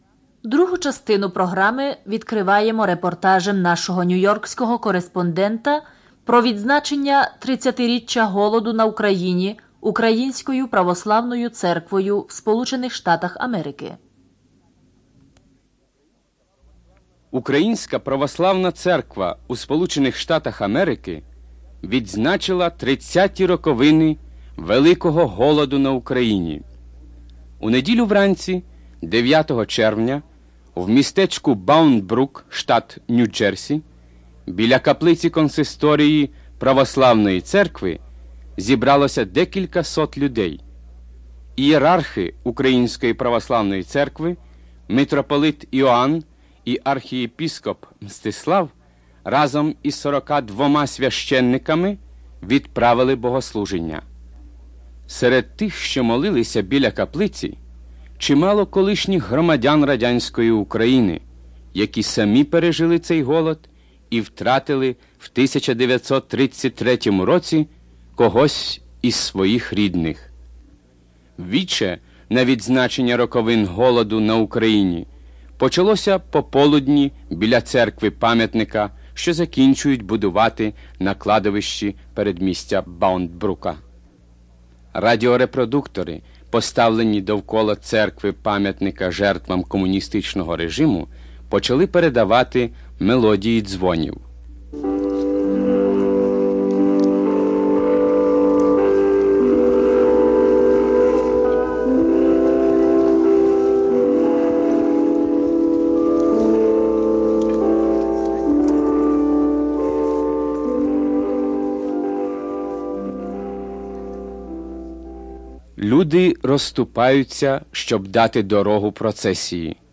Report on the commemoration of the 30th anniversary of the Holodomor by the UOC of USA
Speech by Archbishop Mstyslav